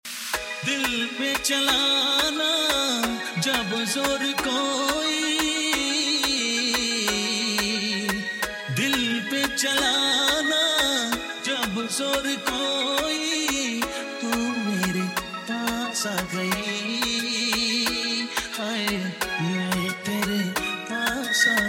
this one’s a global vibe